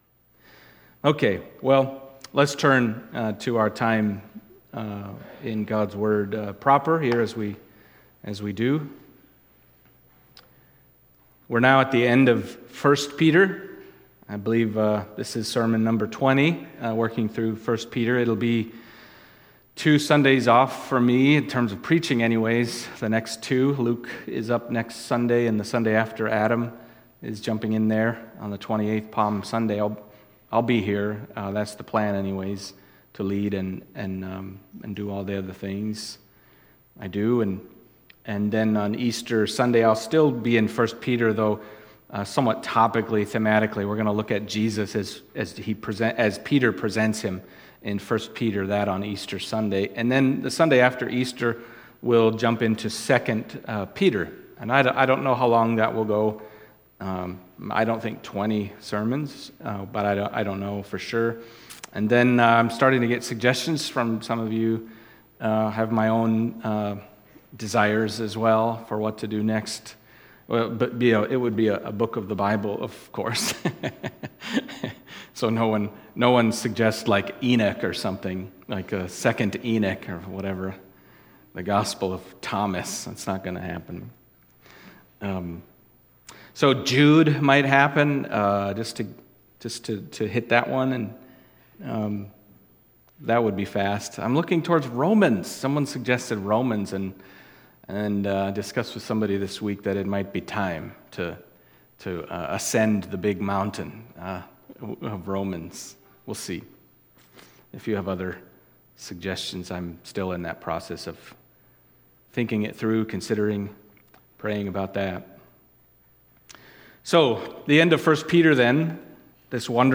1-2 Peter Passage: 1 Peter 5:10-14 Service Type: Sunday Morning 1 Peter 5